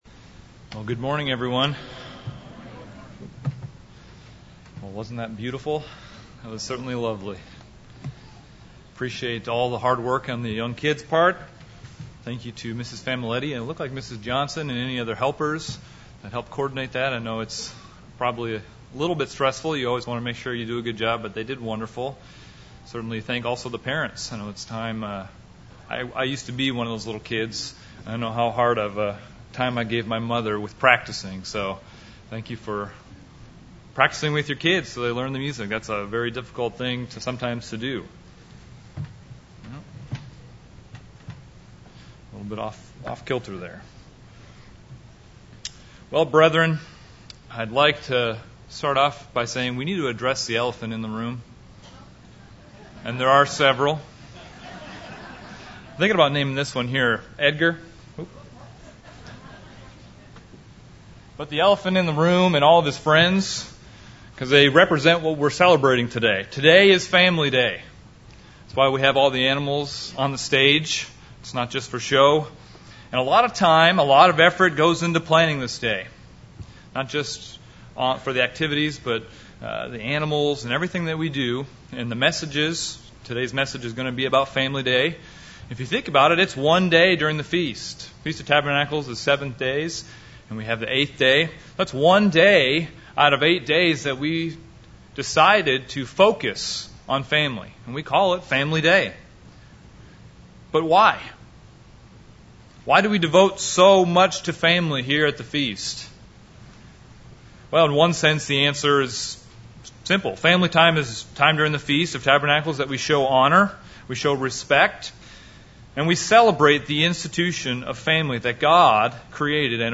This sermon was given at the Branson, Missouri 2017 Feast site.